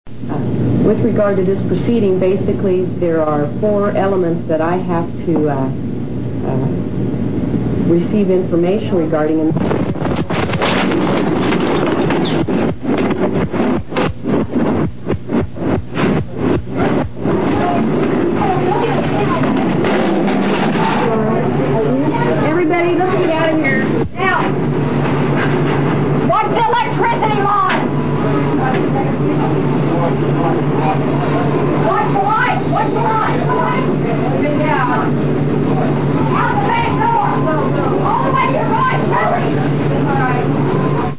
One very stirring part of the memorial is when you walk in and get to listen to the hearing that was recorded at the Oklahoma City Water Board.  You can hear the  proceedings taking place, then the sound of the explosion is recorded.
It isn't the best quality, but it conveys the message.